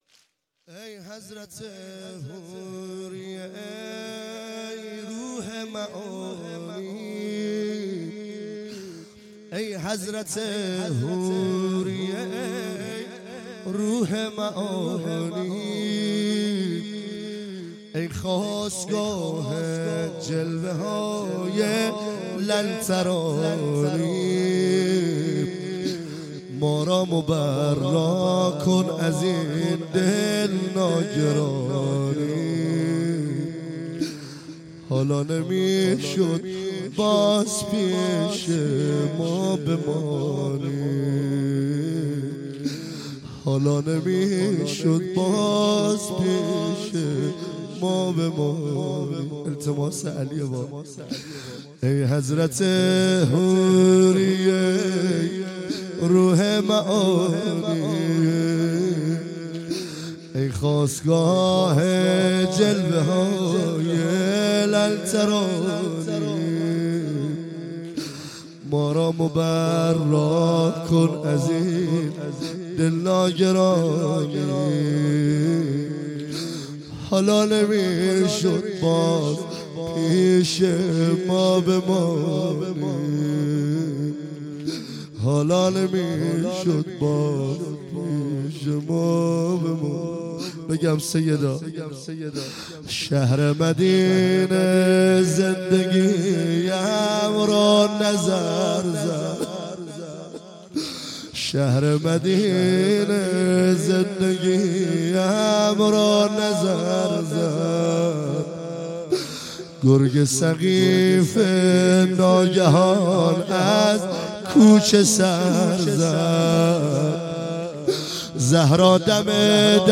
فاطمیه
واحد مداحی